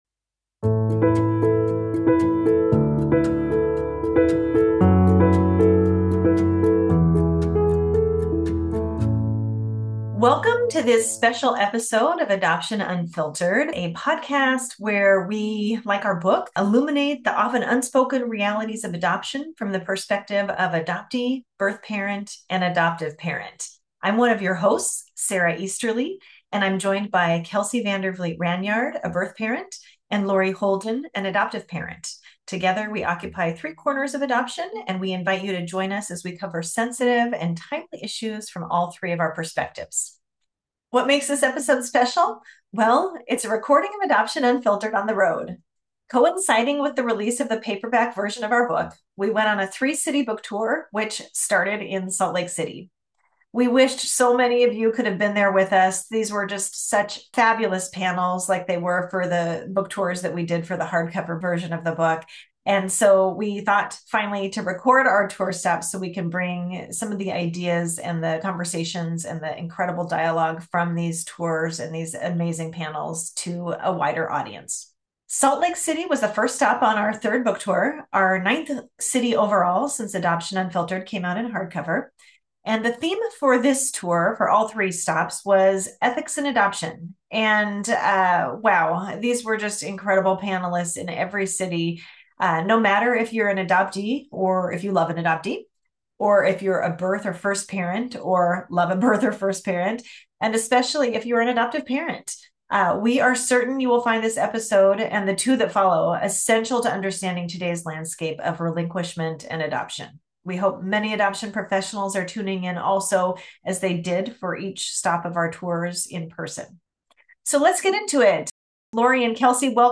This episode finds Adoption Unfiltered on the road for its "Ethics in Adoption" tour. Our first stop on this three-city tour could be none other than Salt Lake City, Utah.